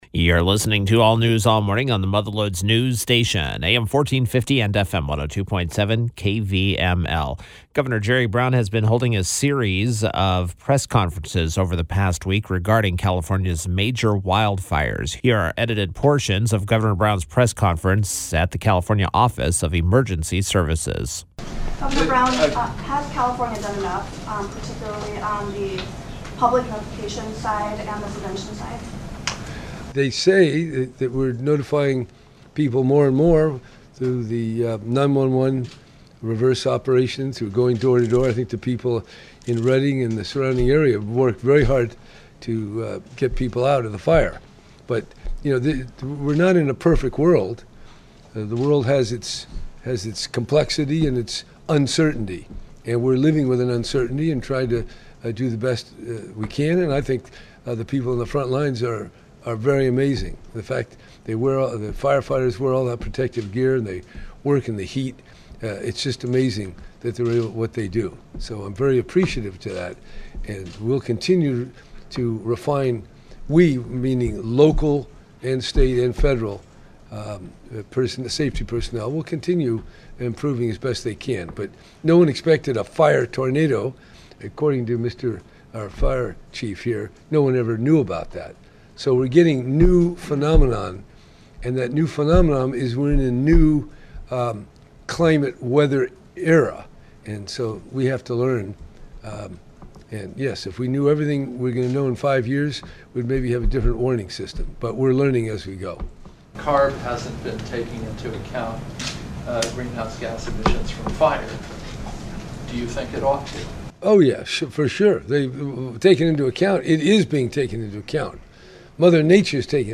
Brown was Friday’s KVML “Newsmaker of the Day”.